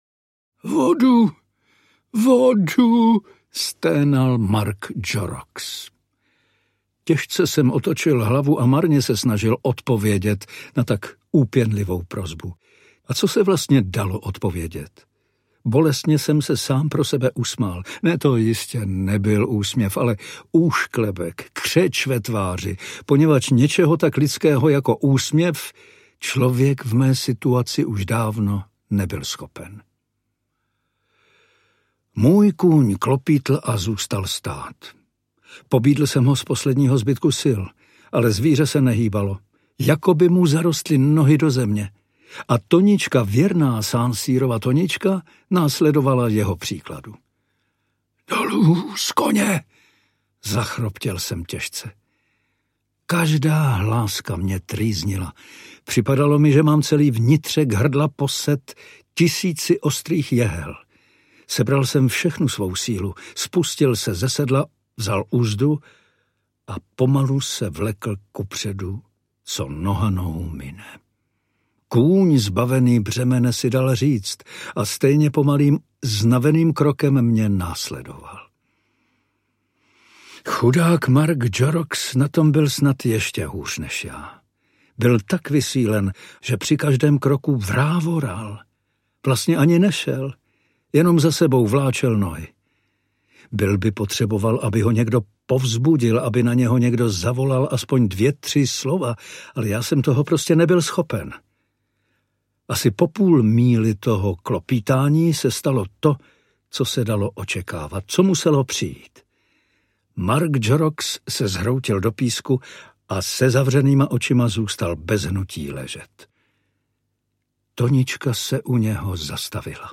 Vinnetou – Poslední výstřel audiokniha
Ukázka z knihy
• InterpretPavel Soukup